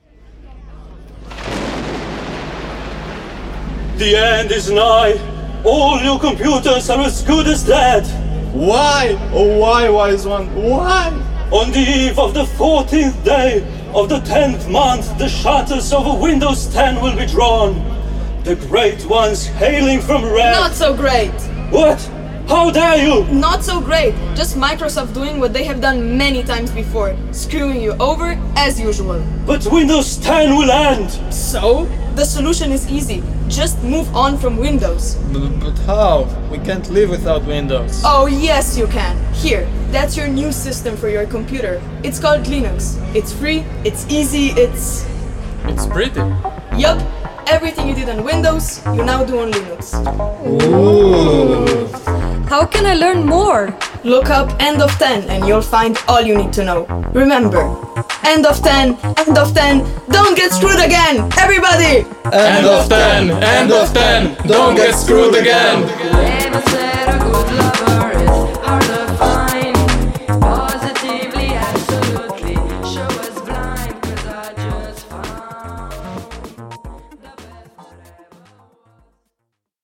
We recorded the jingle today :)